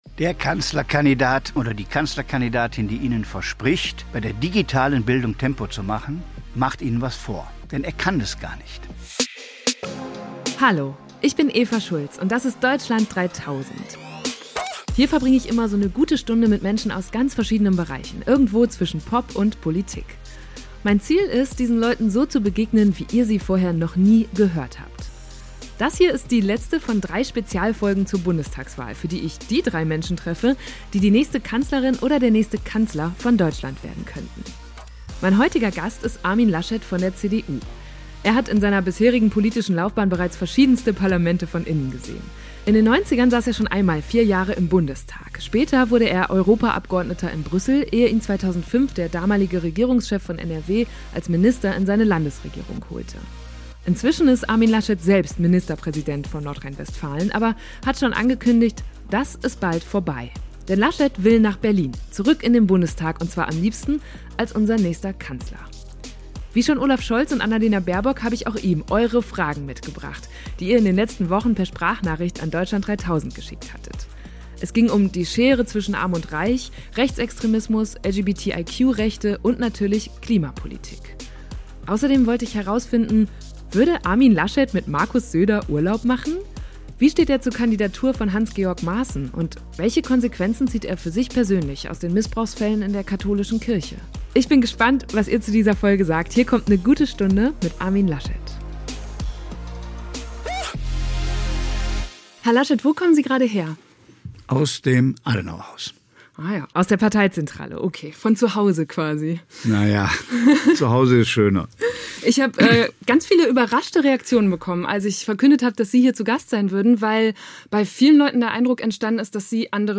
Mein heutiger Gast ist Armin Laschet von der CDU.